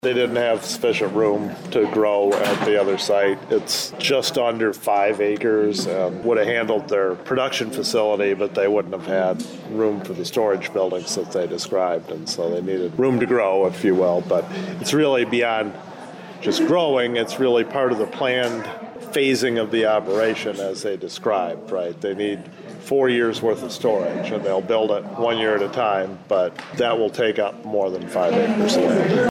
Mammoth Distilling was looking at property in the City of Adrian Industrial Park, but the space was deemed too small for their entire operation… explained City Administrator Greg Elliott…